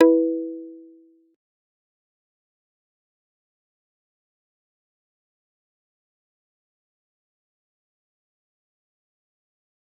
G_Kalimba-E4-pp.wav